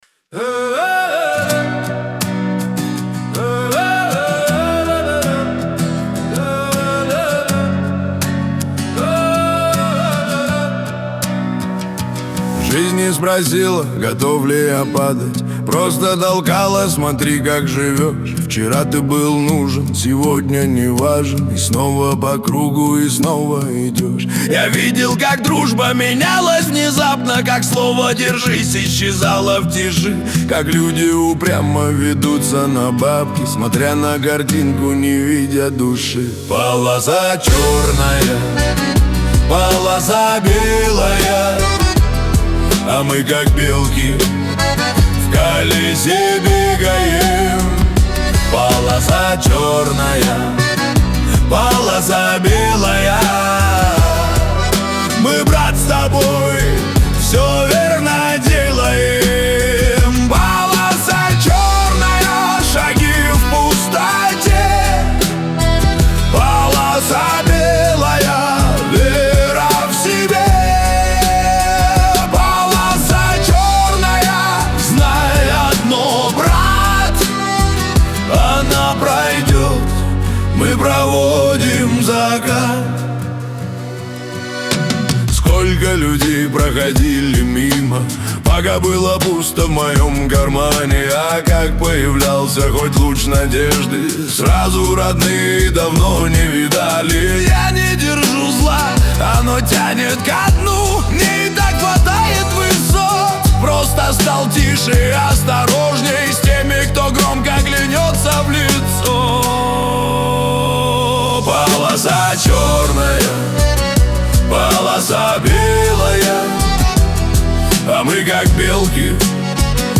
Лирика
Шансон
грусть